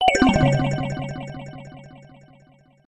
鉄琴の一種のヴィブラフォン音色。